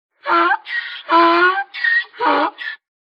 PixelPerfectionCE/assets/minecraft/sounds/mob/horse/donkey/angry1.ogg at mc116